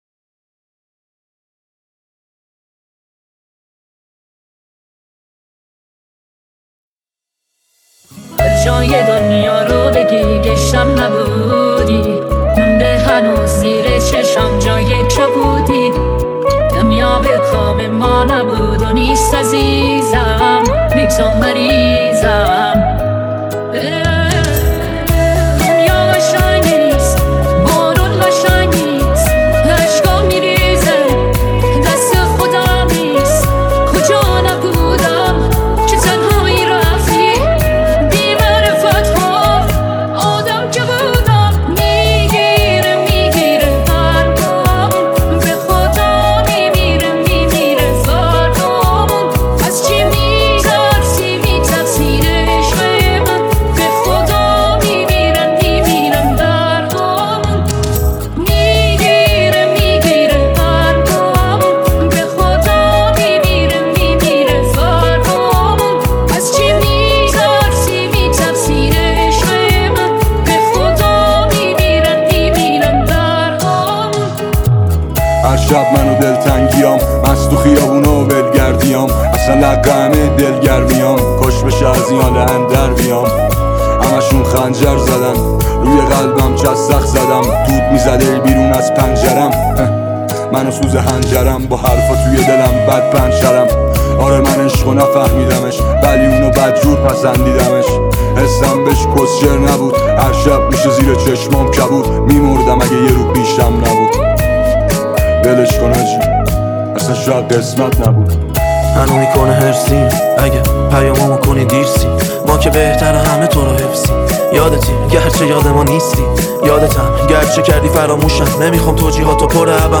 دانلود ریمیکس جدید رپ
Remix Rapi